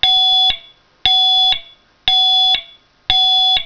Sirena Multitonal 8 Sonidos
102dB